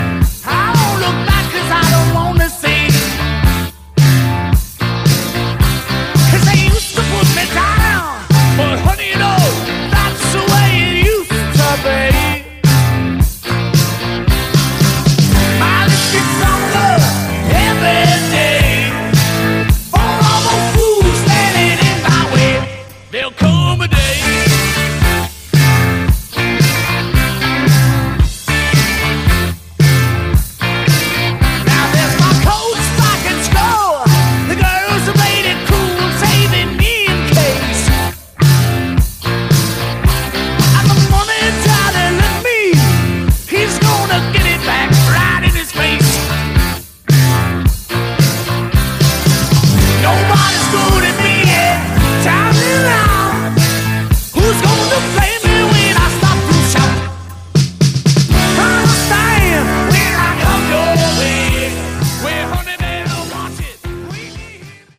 Category: Hard Rock
guitars, keyboards
drums, percussion